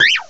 sovereignx/sound/direct_sound_samples/cries/jangmo_o.aif at master